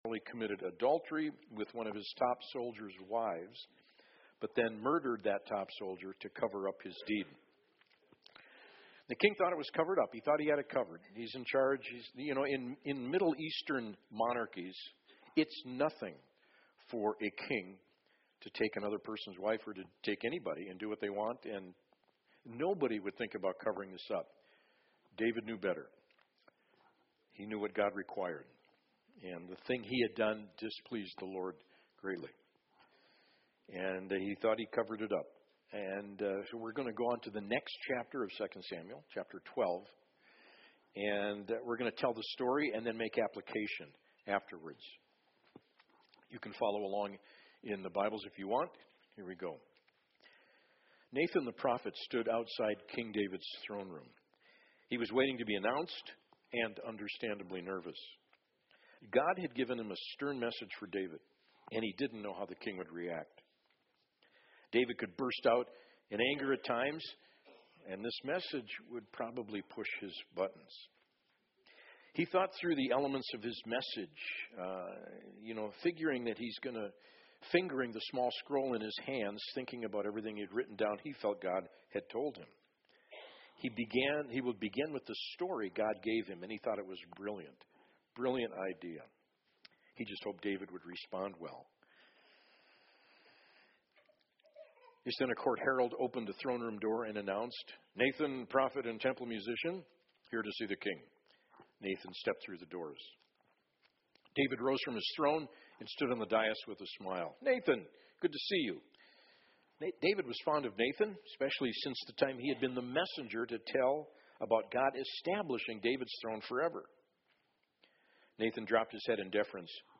SERMONS Consequences and Confession David - Mighty Man of God - A Series in 2 Samuel January 16